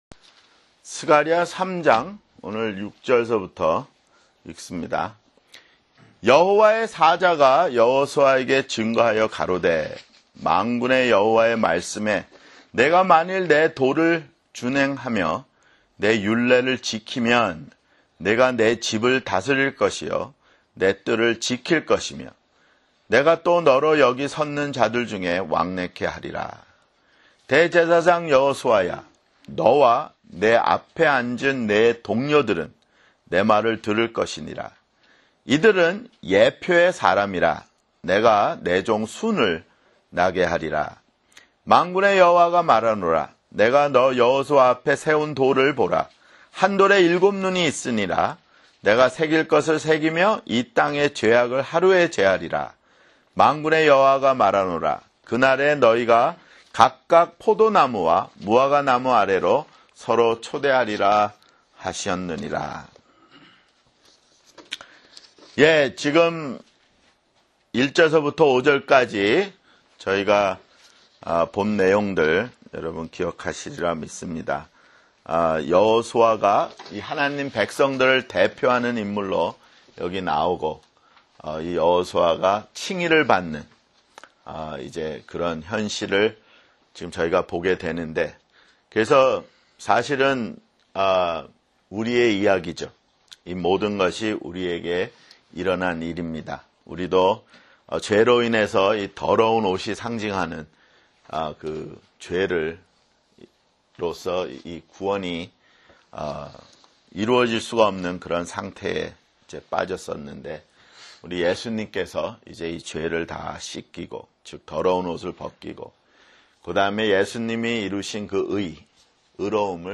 [성경공부] 스가랴 (23)